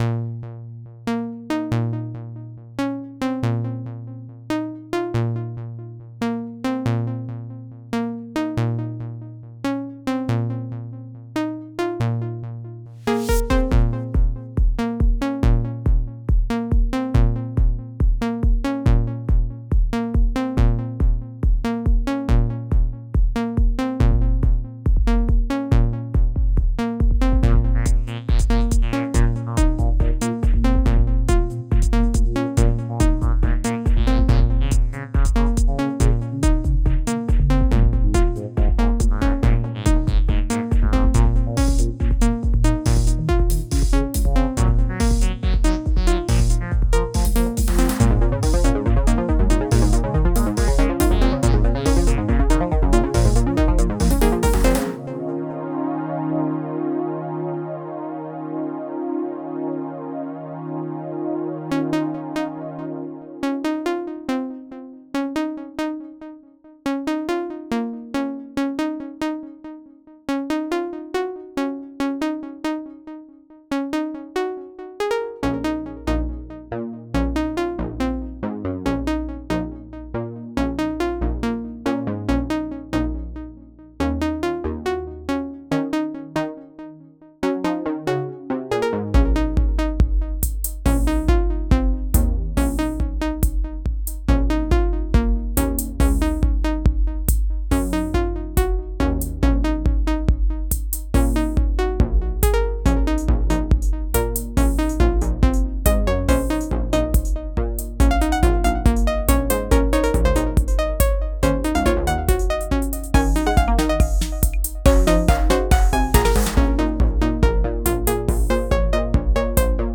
Just a Nanostudio trance track
I used Zeeon for the pluck (and its delay) and the acid sound (which was gated using FAC Envolver), VAMono for the bass (modulated by FAC chorus), Tera Pro for the pad (and a duplicate layer gated by Envolver), Model D + FAC Phazer for the solo lead, and Slate for the drums (which used the internal compressor).
It was a bit hard to mix it without having some clipping, but let me know what you think before I put it on SoundCloud!